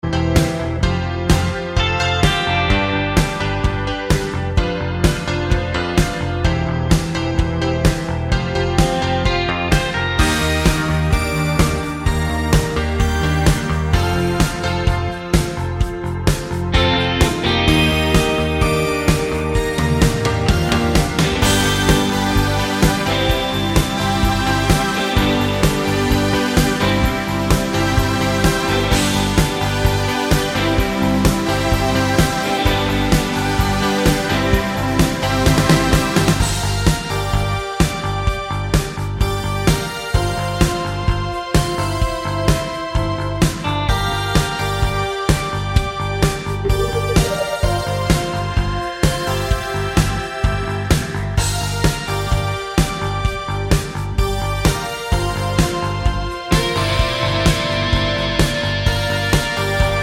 no Backing Vocals Indie / Alternative 4:33 Buy £1.50